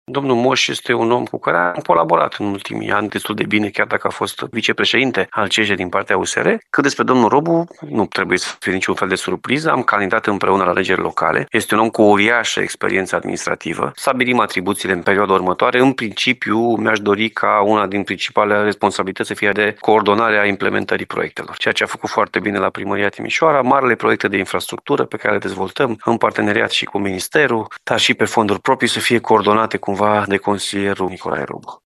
Alfred Simonis a explicat pentru Radio Timișoara ce l-a determinat să îi aleagă drept consilieri personali pe Robu și Moș.